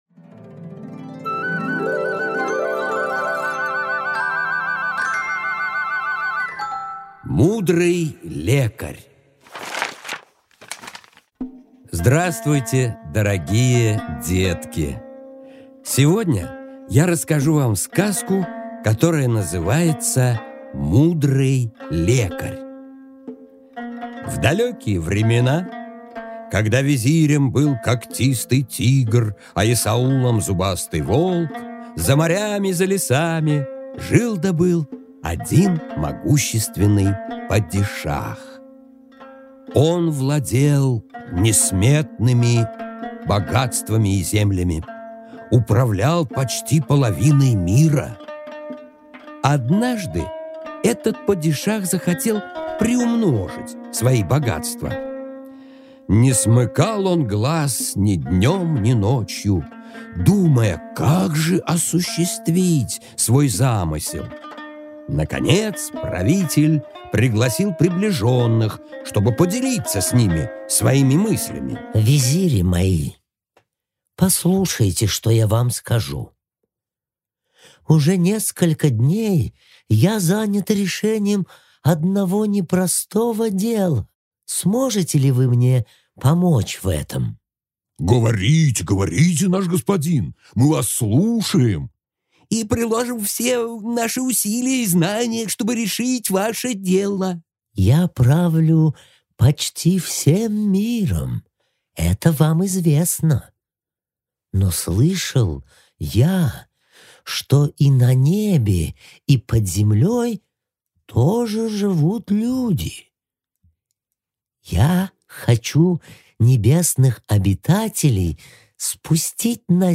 Аудиокнига Мудрый лекарь